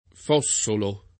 Fossolo [ f 0SS olo ]